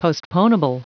Prononciation du mot postponable en anglais (fichier audio)
Prononciation du mot : postponable